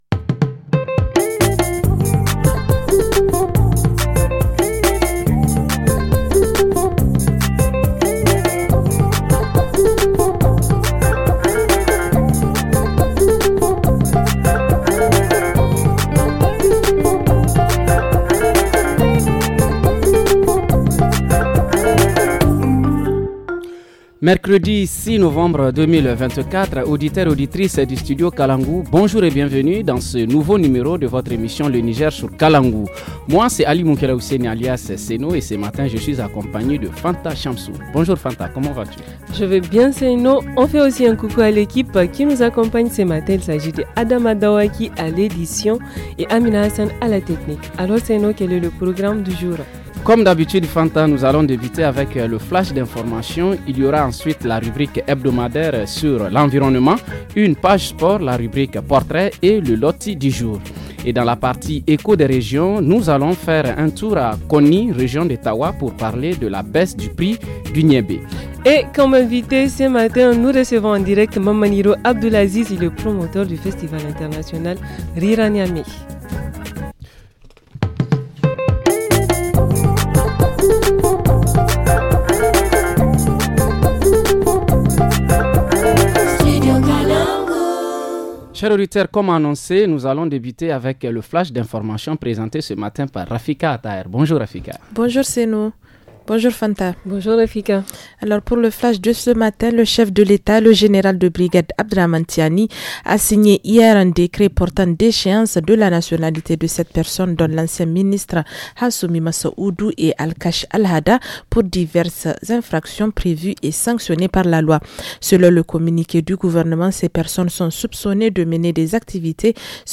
Dans la rubrique hebdomadaire, zoom sur les défis de la transhumance aux frontières sud du Niger, à Tanout. En reportage région, s’intéresse à la réduction du prix du Niébé à Birnin Konni.